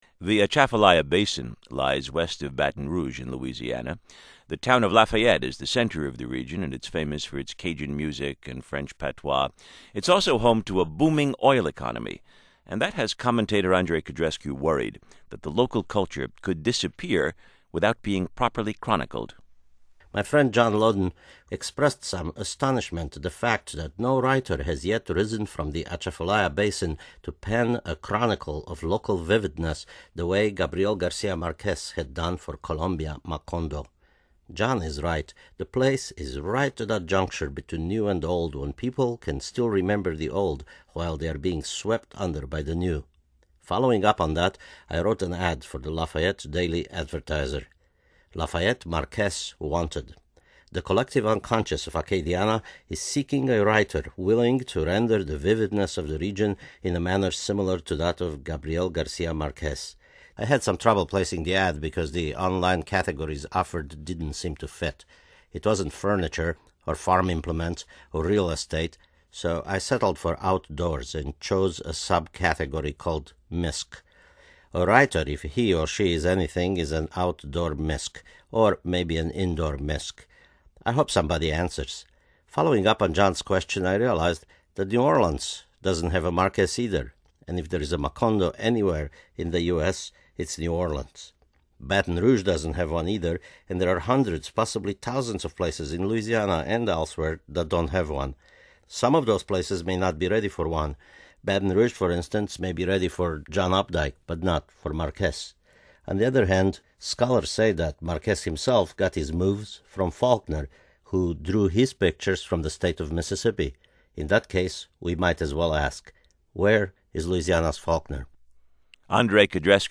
Codrescu’s agreement were the topic of his NPR commentary today: